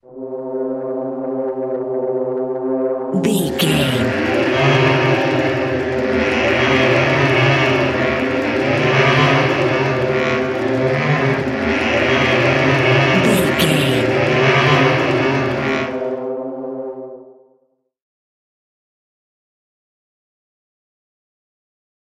In-crescendo
Thriller
Atonal
ominous
dark
eerie
synths
Horror Synths
atmospheres